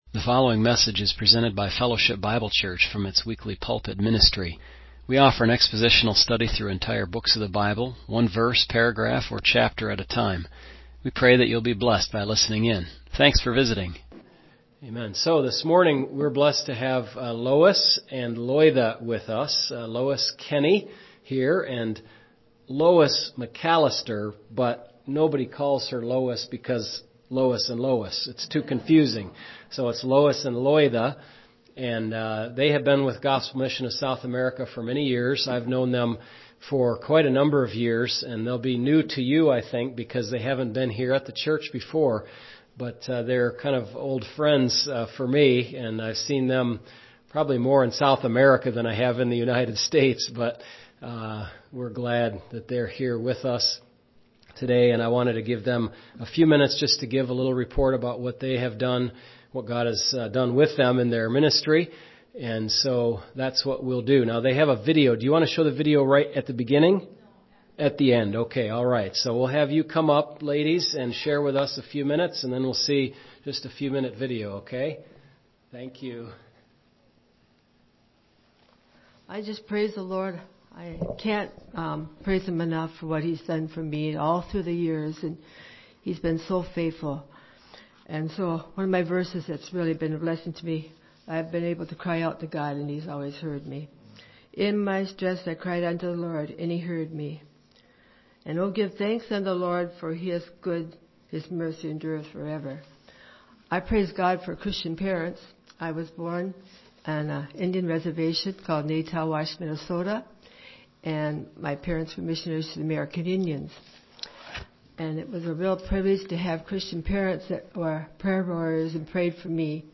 MP3 recordings of sermons and Bible studies for the Sunday ministries at the church.
They gave their testimonies for us today during Sunday School. Towards the second half of Sunday school, we learned how Peter encouraged believers with this letter reminding them to renew their minds with the Word of God through obedience.